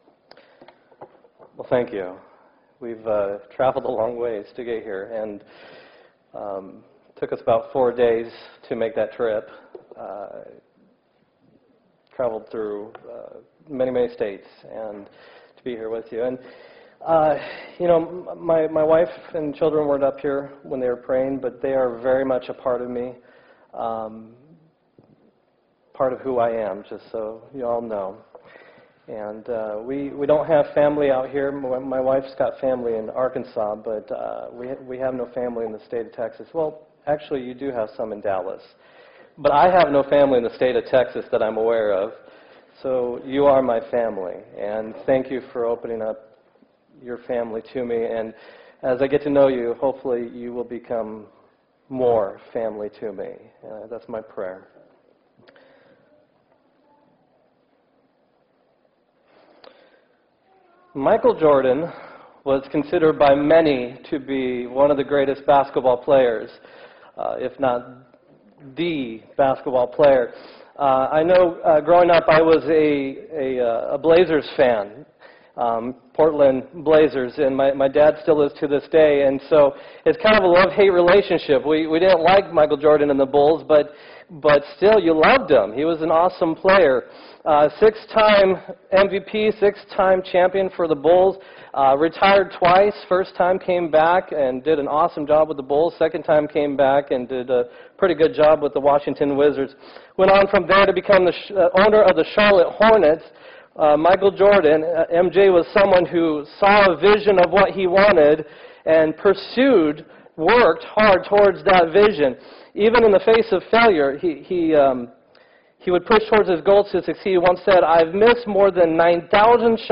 8-8-2015 sermon